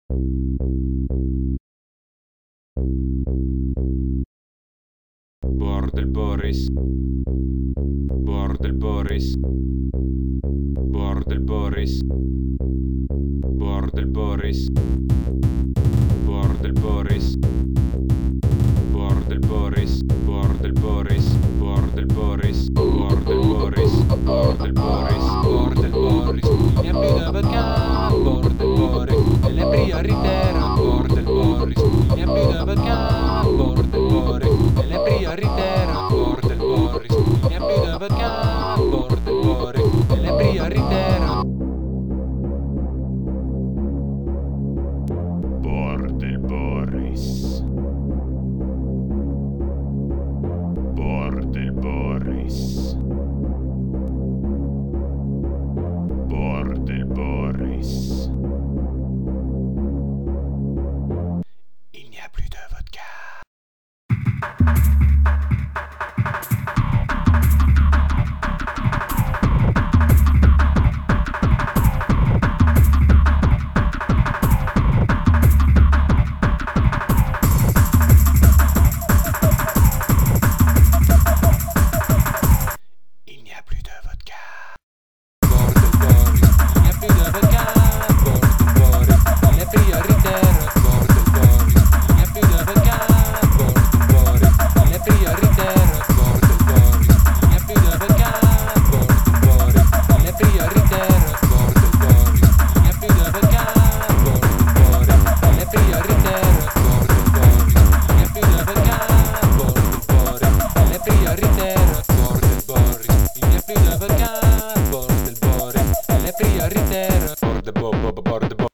La version techno